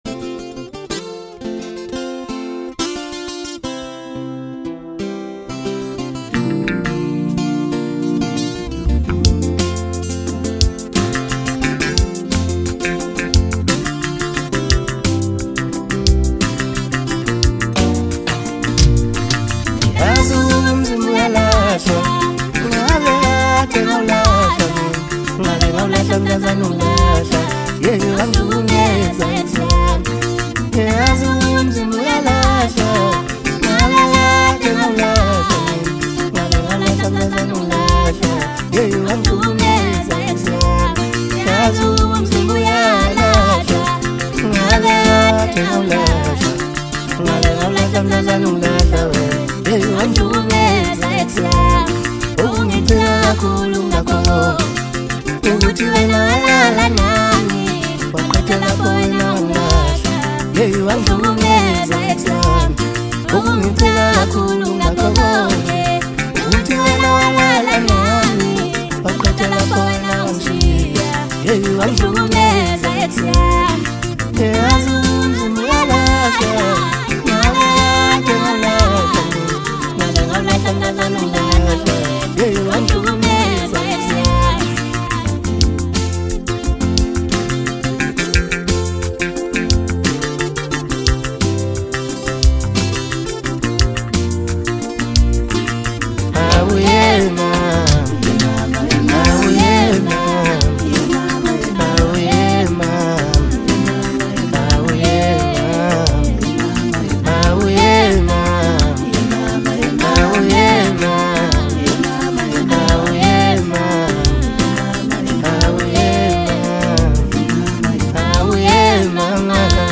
Maskandi, DJ Mix, Hip Hop